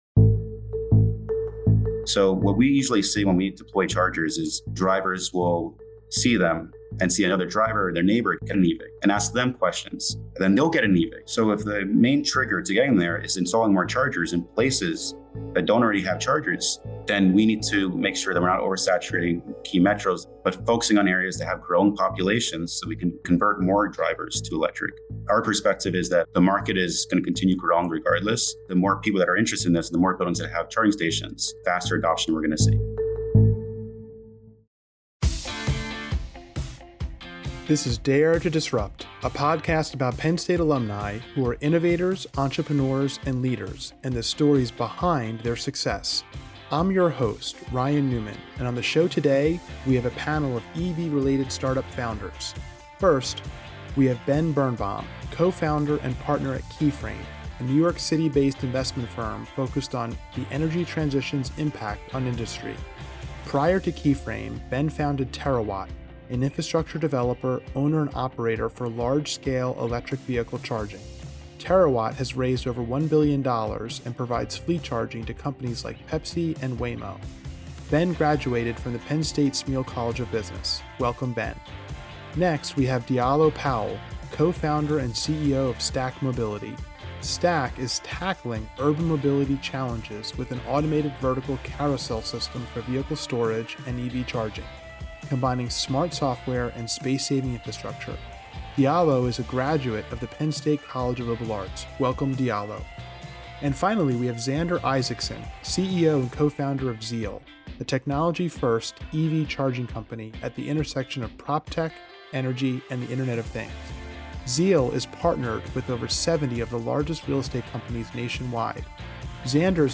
LIVE from the Venture & IP Conference: Innovating the Future of EV Infrastructure - Invent Penn State